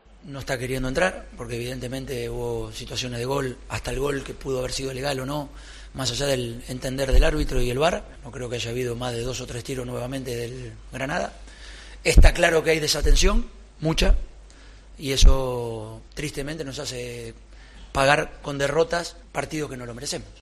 Simeone, en rueda de prensa: "Hay desatención, estamos pagando con derrotas partidos que no lo merecemos"
El entrenador del Atlético de Madrid ha comparecido ante los medios tras el partido frente al Granada.